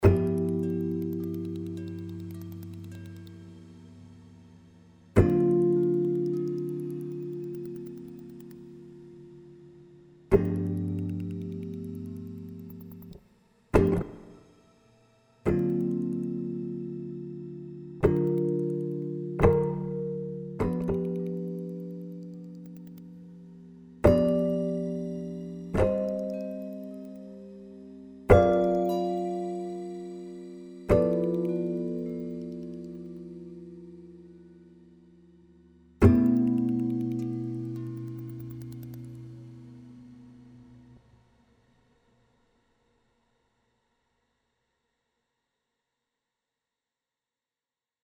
Bevor wir uns von ihnen verabschieden, noch etwas Sanfteres.
Wir kommen zum Pianet T:
Externe Mitspieler: Auch im folgenden Audiodemo sind wieder Toonrack-MIDI-Pattern dabei, dieses Mal Jazz-Akkorde. Für den Hintergrund sorgen zufällige disharmonische Arpeggios aus Soundiron Tuned Artillery. Hier wird auf einer (vermutlich entschärften) Granate und einem Helm aus dem zweiten Weltkrieg sanft getrommelt. Die Cluster entstehen durch den Arpeggiator innerhalb von Tuned Artillery und Replika XT von Native Instruments, welches zusätzlich eine Art Grain-Hallwolke spendiert.